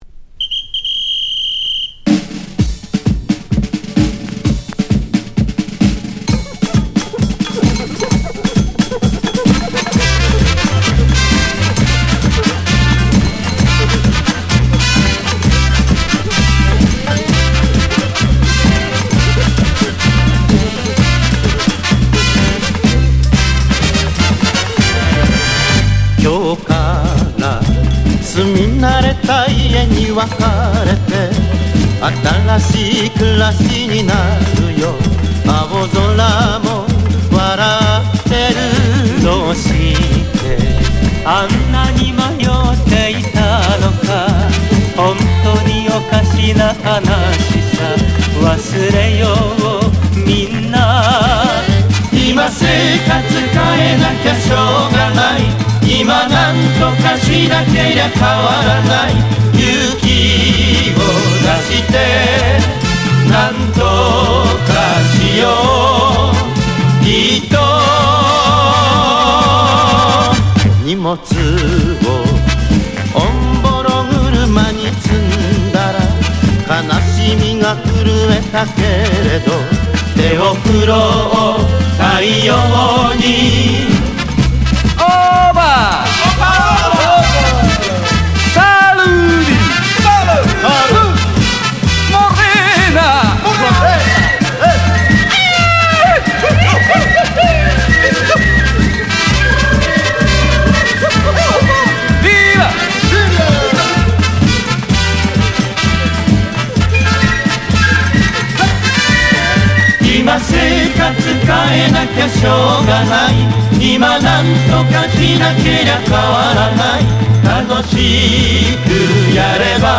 サンバのリズムにのって
ディープ・ムード・コーラス珍曲！